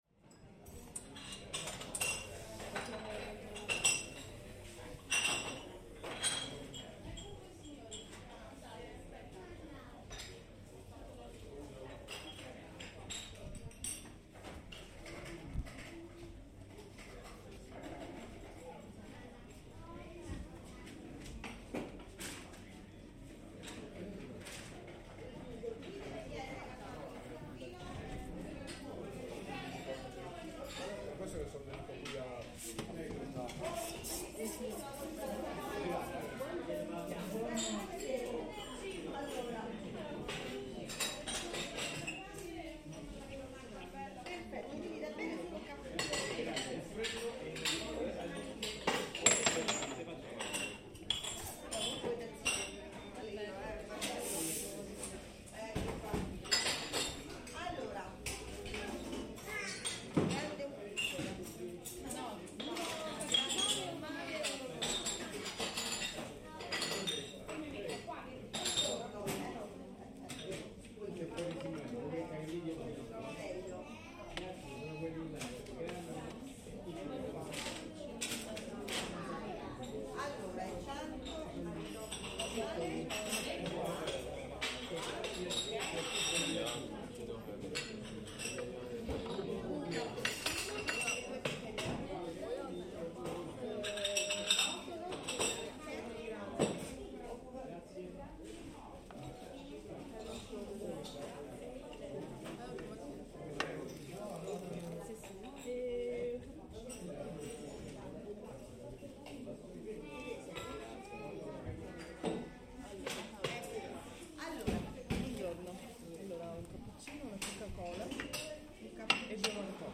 Waiting in line for maritozzi at the famous Regoli Pasticceria. Cups and plates clink, coffee is being made, and customers and the serving staff chat at the bar.